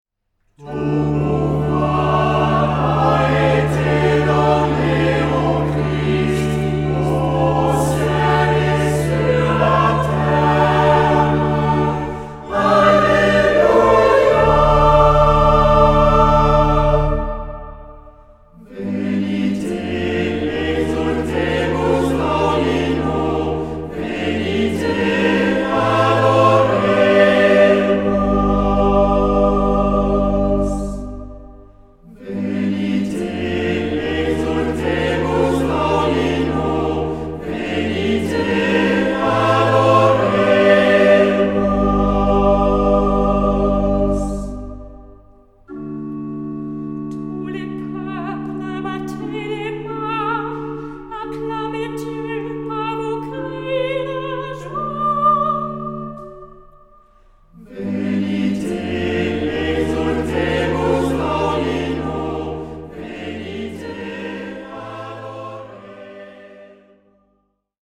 Genre-Style-Forme : Sacré ; Tropaire ; Psaume
Type de choeur : SATB  (4 voix mixtes )
Instruments : Orgue (1)
Tonalité : sol mineur ; ré mode de sol